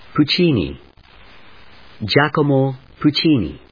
音節Puc・ci・ni 発音記号・読み方
/puːtʃíːni(米国英語), Gia・co・mo dʒάːkəmoʊ(英国英語)/